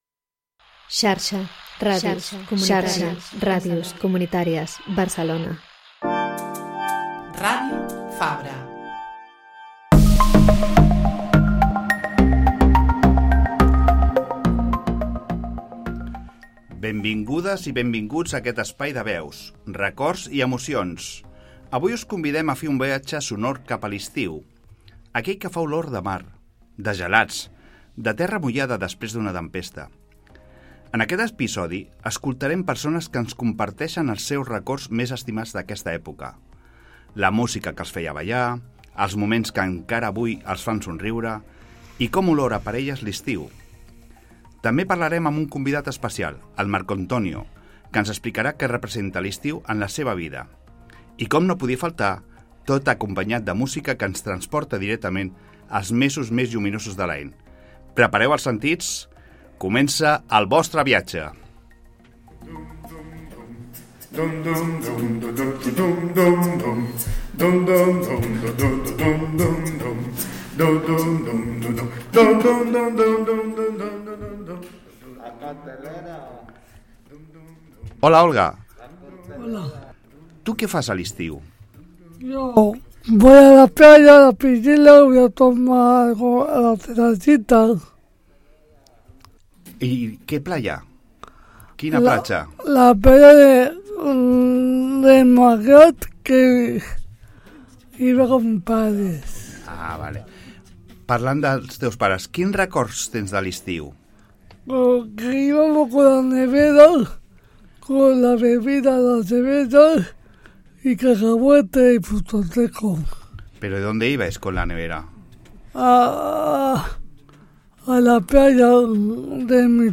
Us convidem a fer un viatge sonor a l'estiu amb diferents veus, records i emocions.